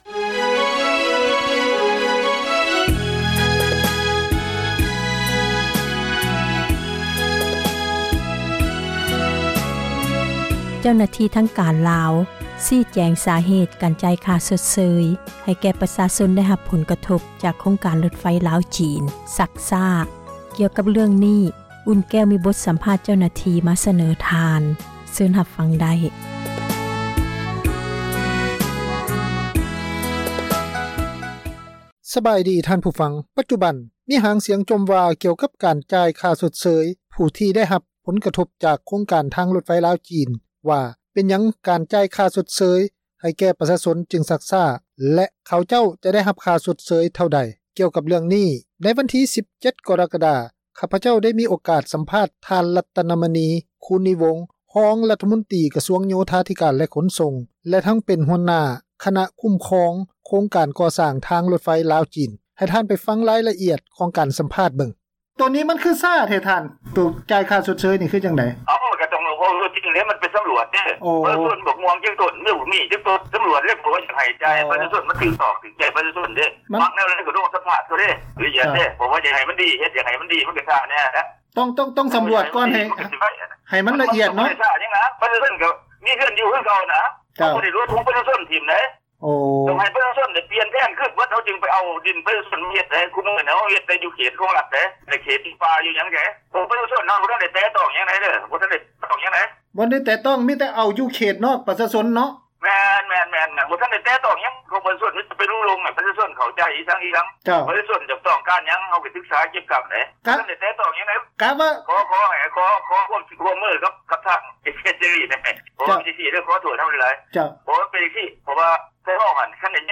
ປັດຈຸບັນຫາງສຽງຈົ່ມກ່ຽວກັບ ການຈ່າຍຄ່າຊົດເຊີຍ ໃຫ້ຜູ້ທີ່ໄດ້ຮັບຜົນກະທົບ ຈາກໂຄງການທາງລົດໄຟ ລາວ-ຈີນ ວ່າເປັນຫຍັງການຈ່າຍ ຄ່າຊົດເຊີຍ ຈຶ່ງຊັກຊ້າ ແລະ ຂະເຈົ້າ ຈະໄດ້ຮັບຄ່າຊົດເຊີຍເທົ່າໃດ. ກ່ຽວກັບເລື້ອງນີ້ໃນວັນທີ 17 ກໍຣະກະດາ ຂ້າພະເຈົ້າ ໄດ້ມີໂອກາດ ສຳພາດ ທ່ານ ລັດຕະນະມະນີ ຄູນນີວົງ ຮອງ ຣັຖມົນຕຼີ ກະຊວງ ໂຍທາທິການ ແລະ ຂົນສົ່ງ ແລະ ທັງເປັນຫົວໜ້າ ຄນະຄຸ້ມຄອງ ໂຄງການ ກໍ່ສ້າງທາງ ລົດໄຟລາວ-ຈີນ: ສຽງສຳພາດ…